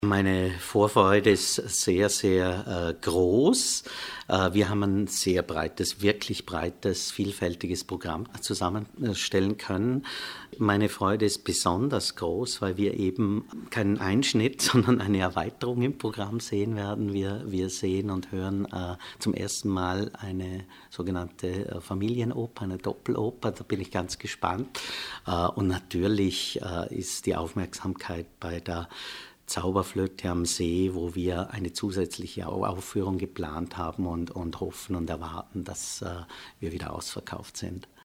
O-Ton Pressetag - News